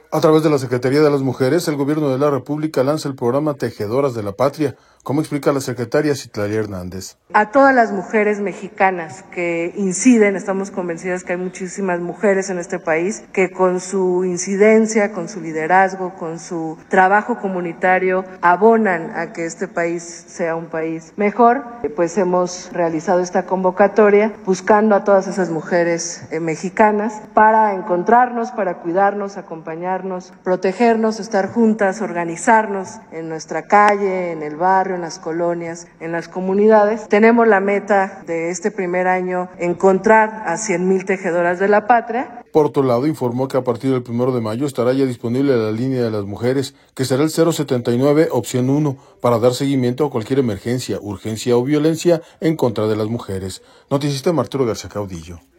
audio A través de la Secretaría de las Mujeres, el Gobierno de la República lanza el Programa Tejedoras de la Patria, como explica la secretaria Citlali Hernández.